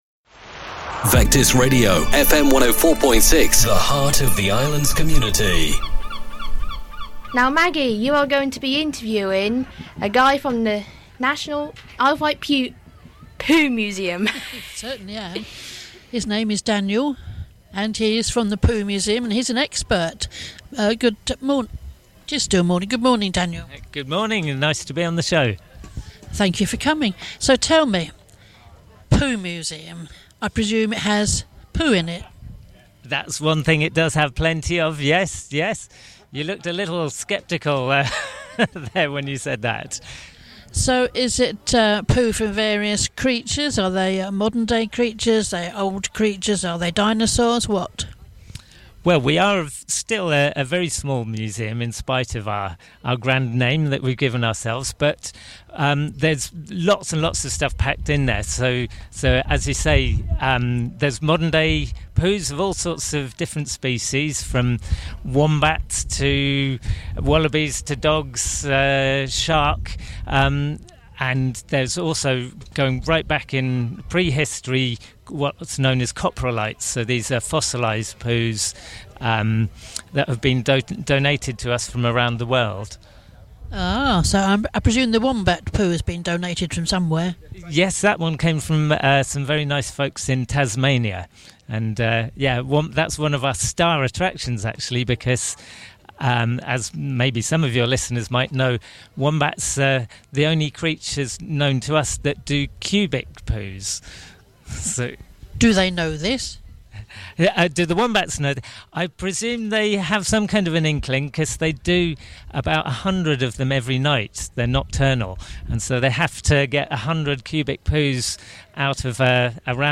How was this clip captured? Interview at the 2022 Royal Isle of Wight County Show.